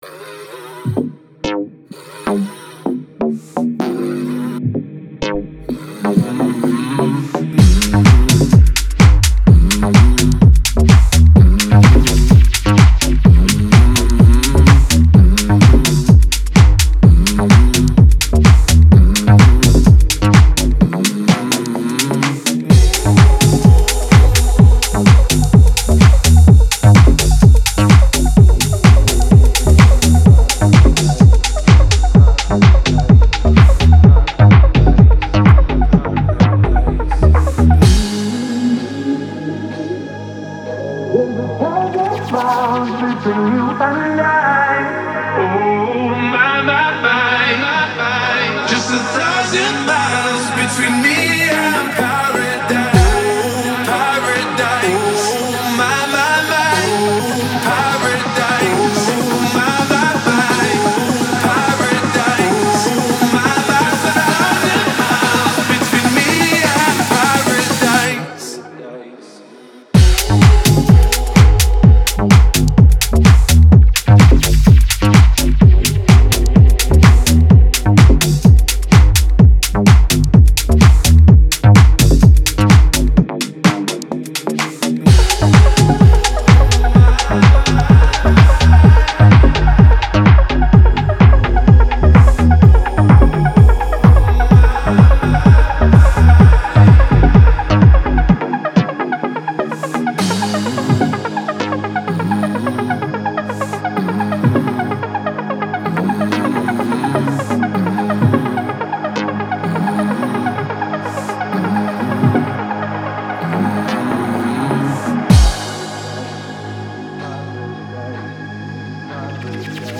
и передает атмосферу мечтательности и надежды.
Эмоциональный вокал